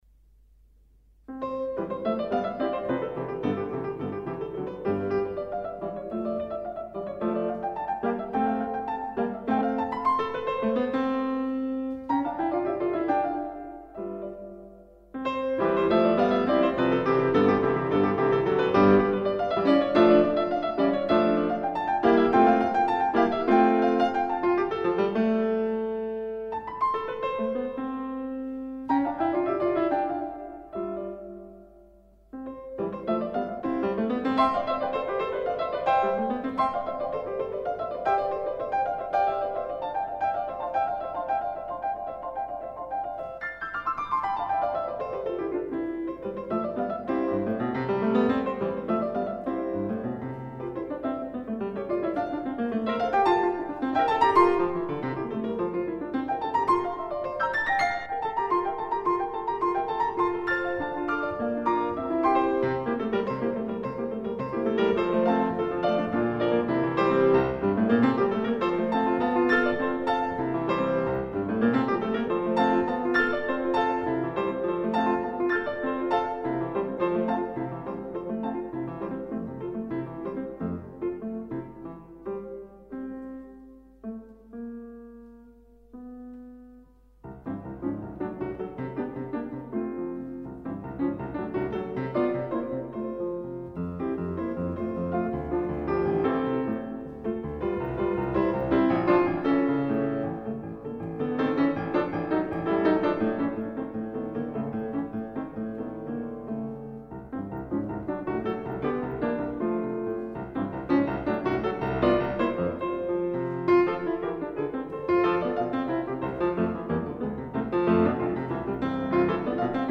03-Esquisses-6-romantiques-for-piano.mp3